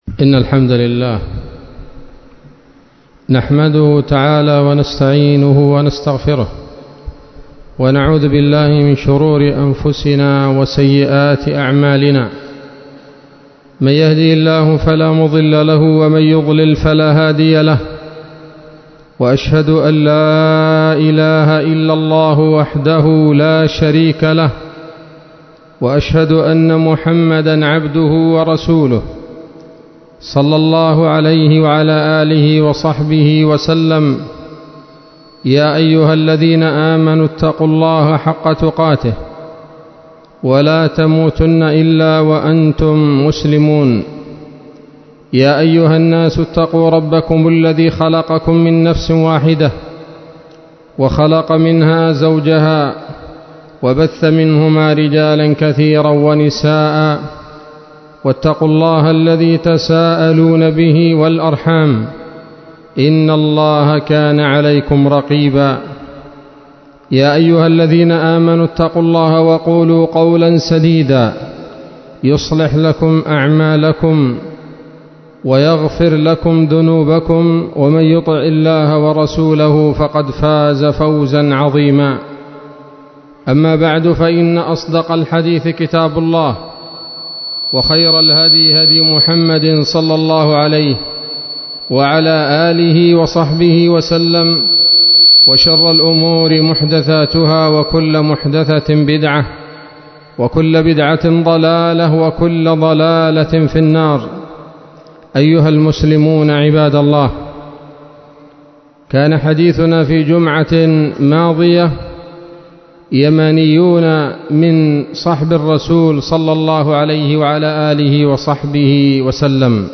خطبة جمعة بعنوان: (( يمانيون من صحب الرسول ﷺ : أبو هريرة الدوسي رضي الله عنه )) 16 ربيع الأول 1443 هـ